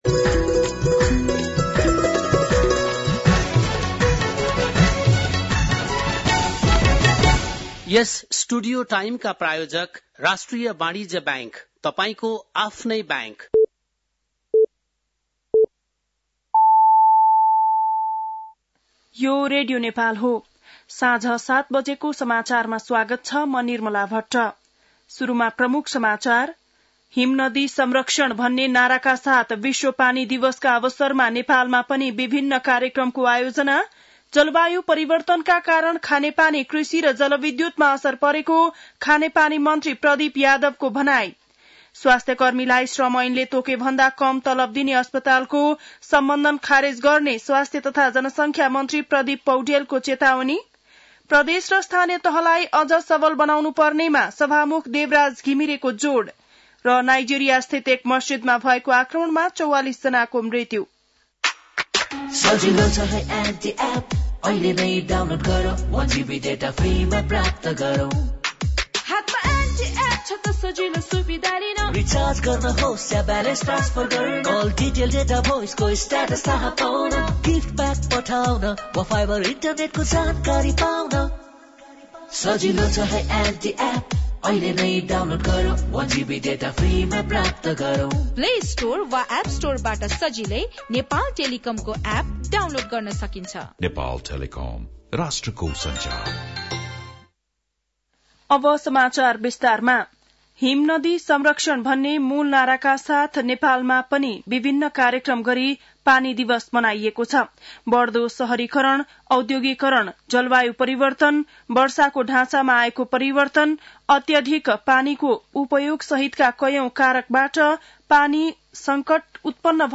An online outlet of Nepal's national radio broadcaster
बेलुकी ७ बजेको नेपाली समाचार : ९ चैत , २०८१